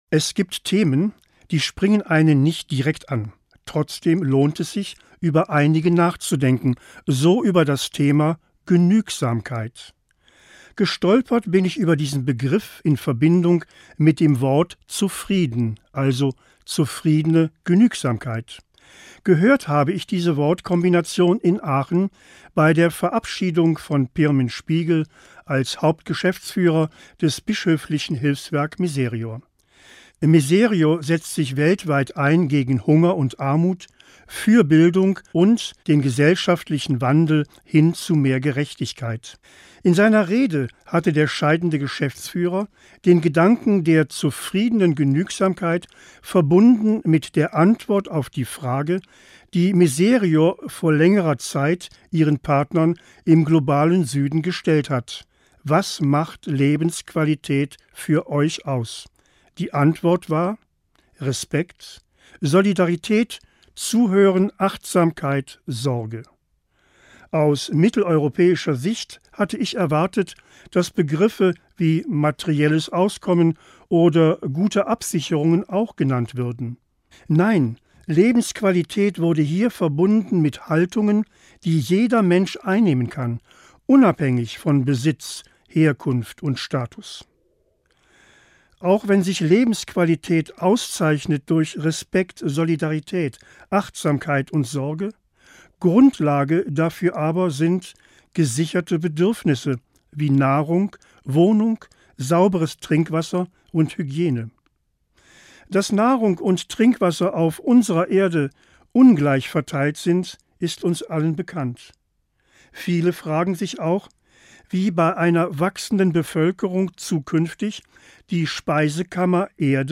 Morgenandacht 10.10.